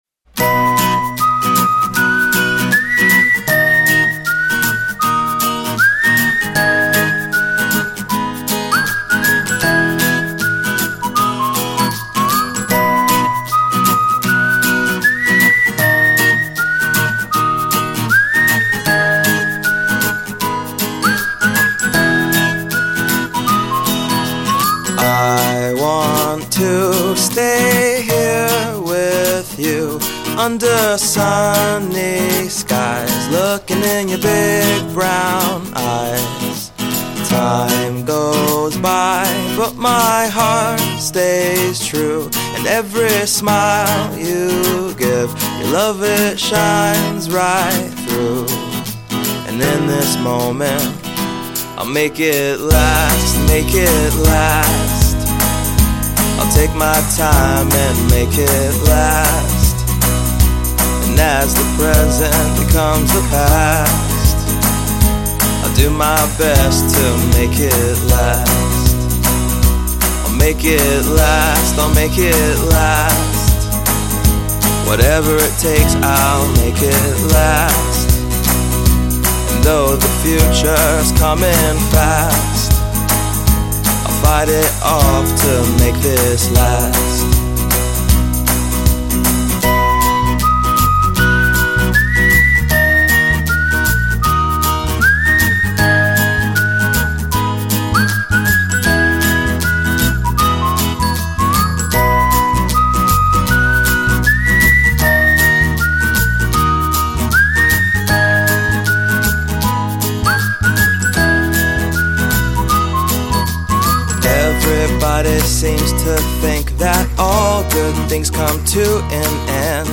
背景音乐为轻松愉快的清晨配乐
该BGM音质清晰、流畅，源文件无声音水印干扰